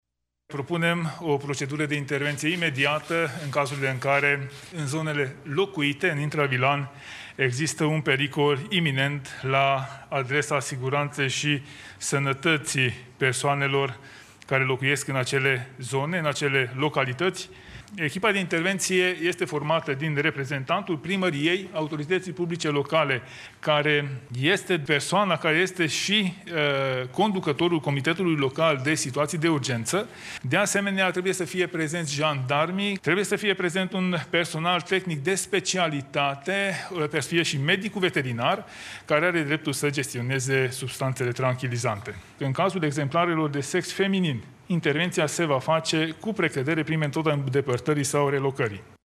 Ministrul Mediului Tanczos Barna: